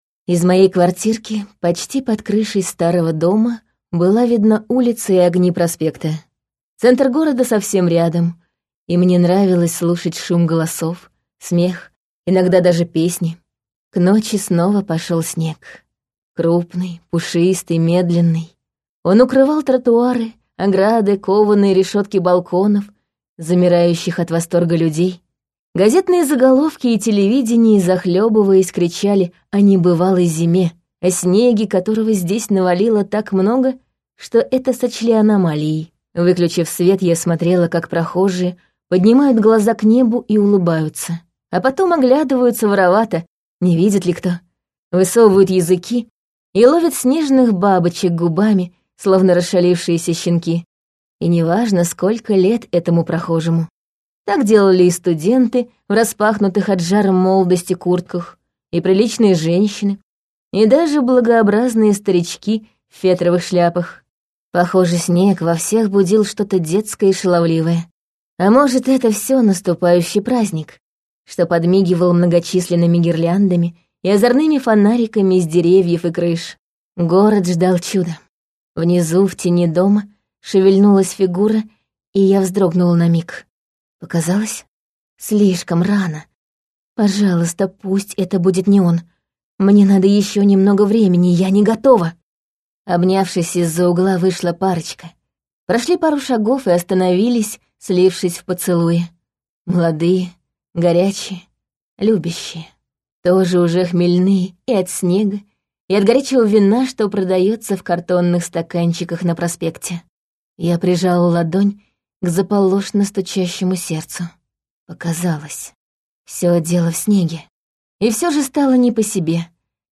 Аудиокнига Тот, кто приходит со снегом | Библиотека аудиокниг